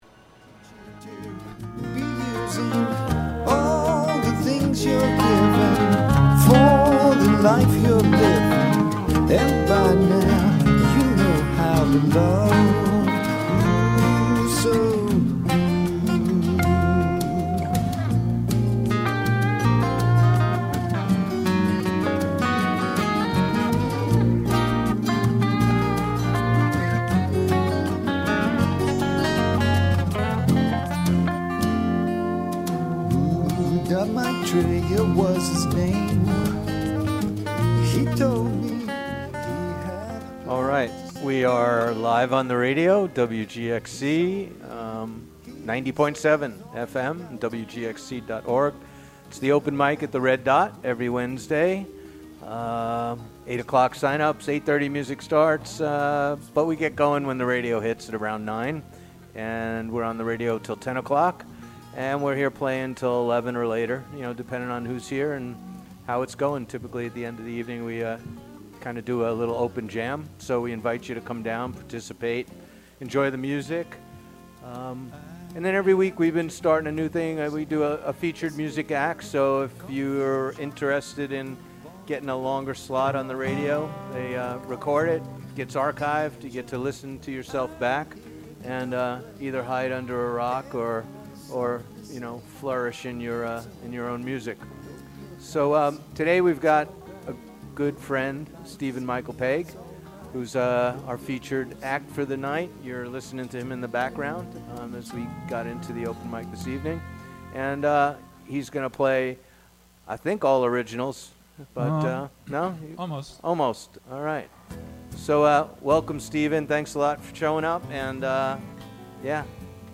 Live from The Red Dot Open Mic